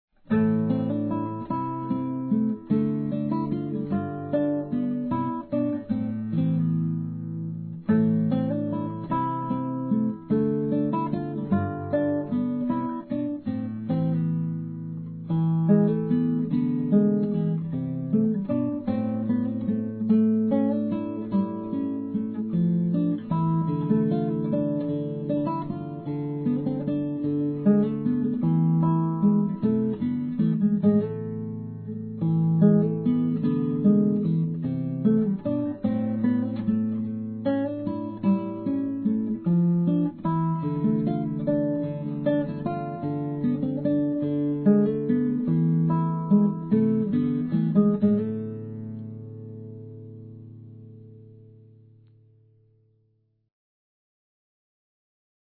MA wedding musician
classical, avant-garde and jazz